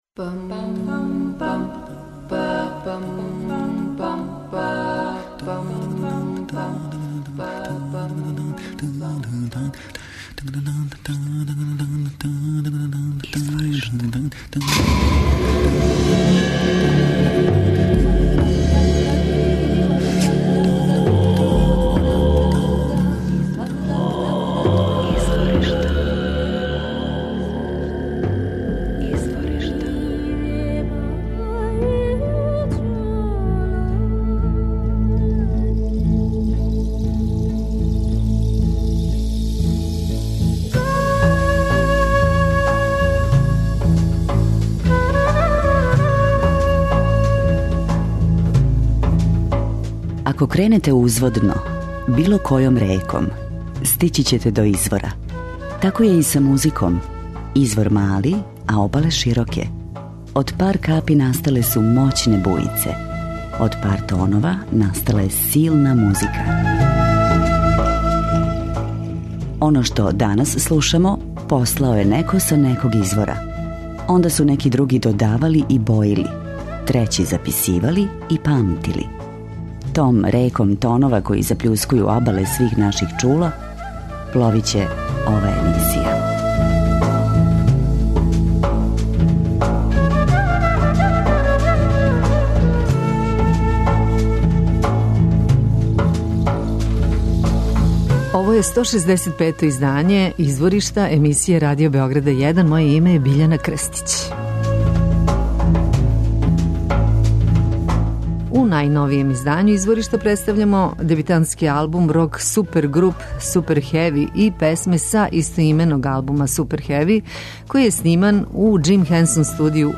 rock supergroup
афрички оркестар
са акустичним инструментима.